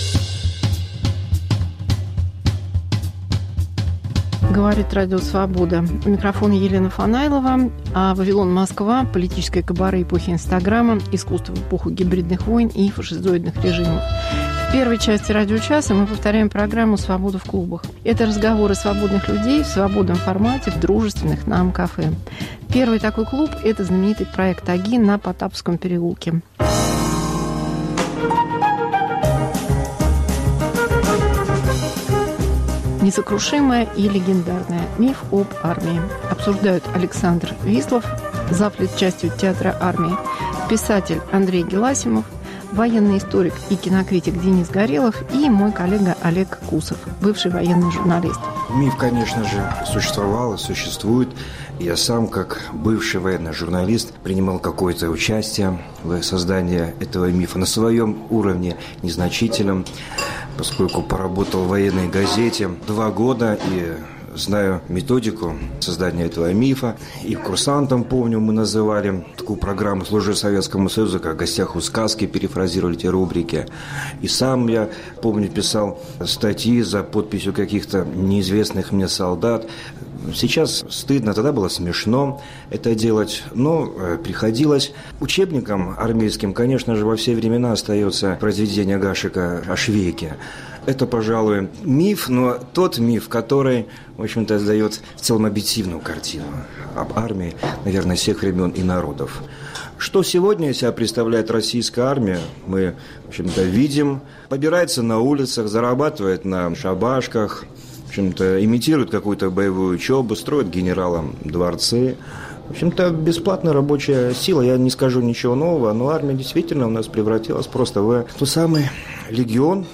Мегаполис Москва как Радио Вавилон: современный звук, неожиданные сюжеты, разные голоса. Свобода в клубах: миф об армии.